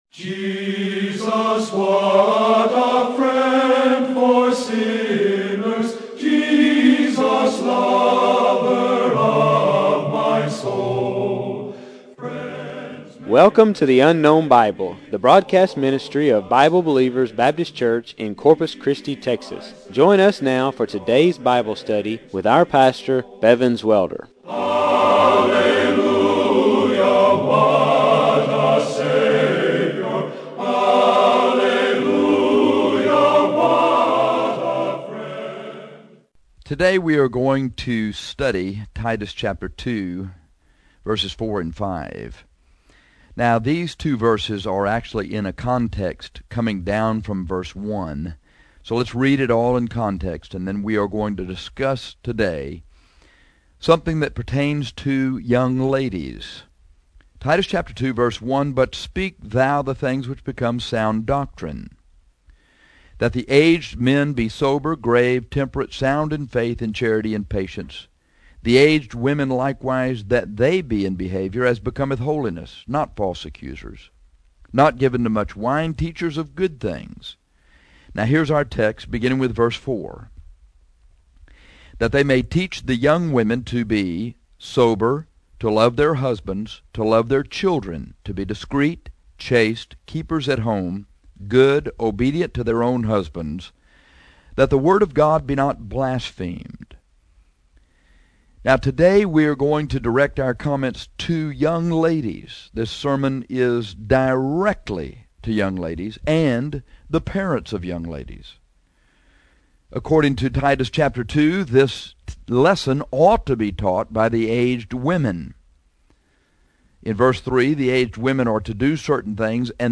This is a sermon directly to young ladies and the parents of young ladies. This lesson ought to be taught by the aged women according to Titus 2.